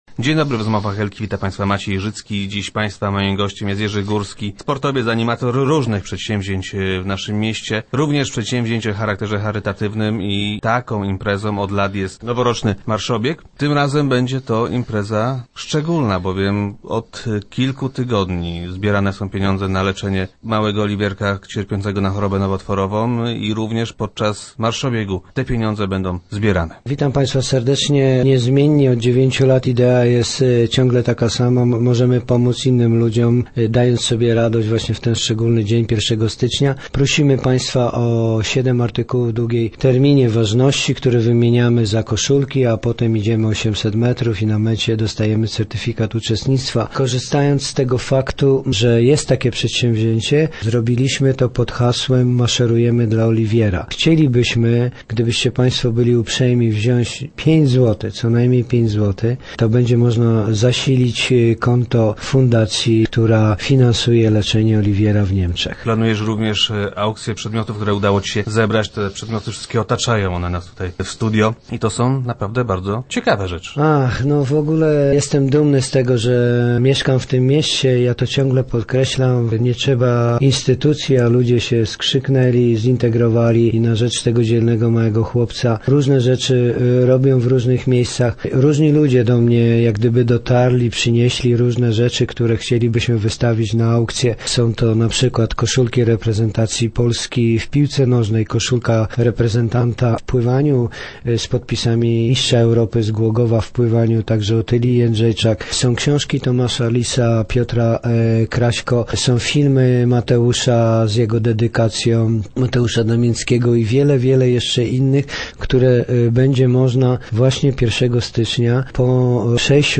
Gościem Rozmów Elki był Jerzy Górski, który zaangażował się w pomoc chłopcu.